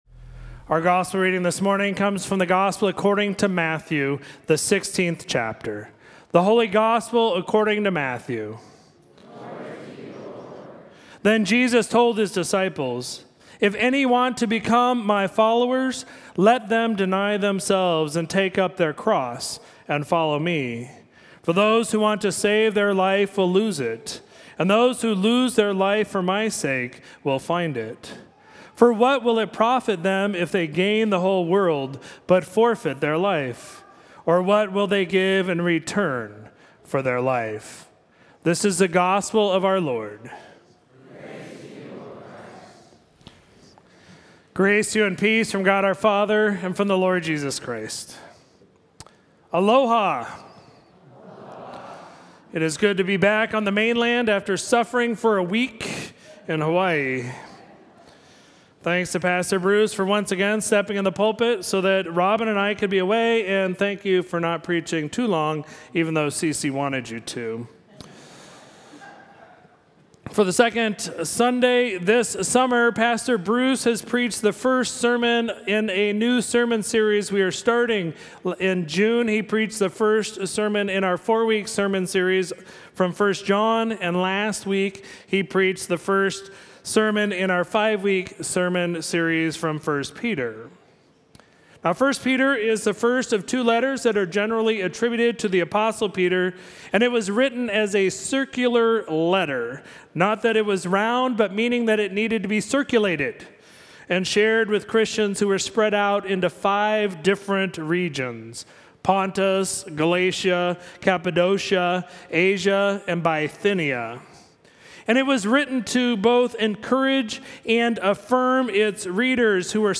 Sermon for Sunday, July 17, 2022